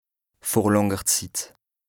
Français Dialectes du Bas-Rhin Dialectes du Haut-Rhin Page